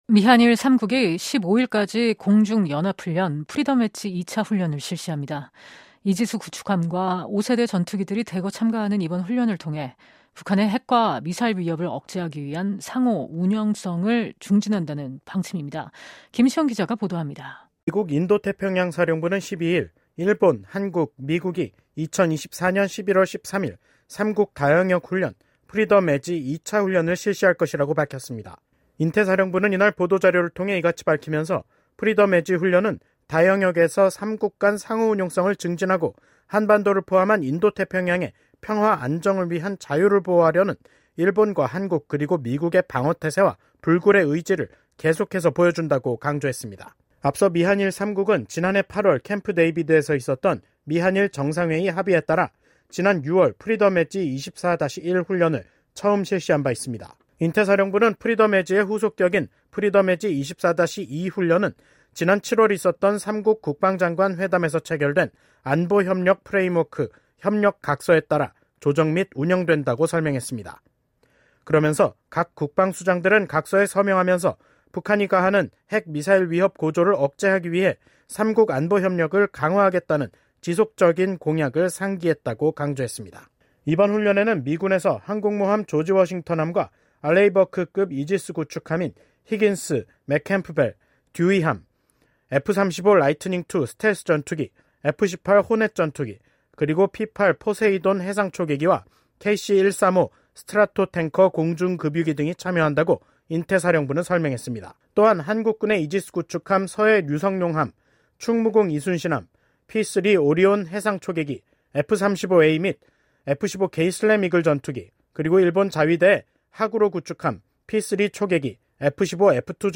보도입니다.